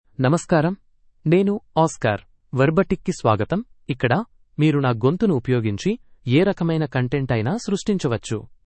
Oscar — Male Telugu AI voice
Oscar is a male AI voice for Telugu (India).
Voice sample
Listen to Oscar's male Telugu voice.
Male
Oscar delivers clear pronunciation with authentic India Telugu intonation, making your content sound professionally produced.